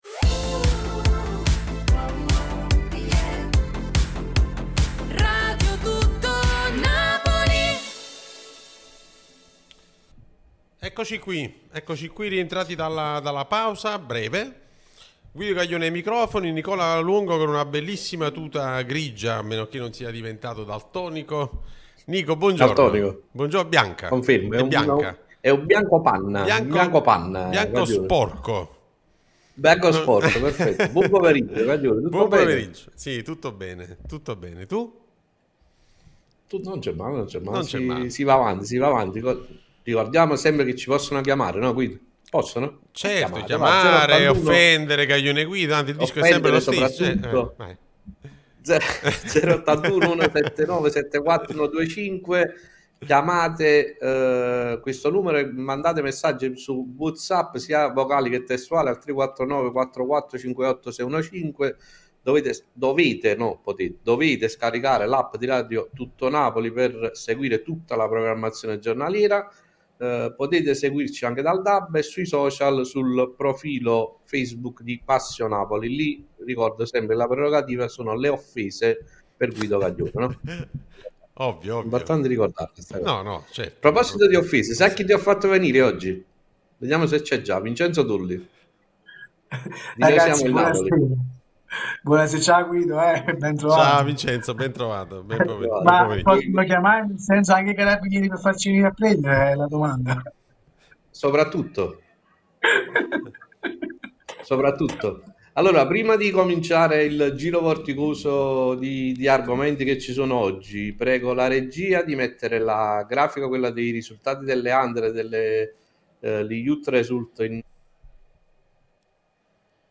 De Bruyne il più grande qui dopo Diego!", discussione a Passionapoli su Radio Tutto Napoli